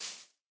grass1.ogg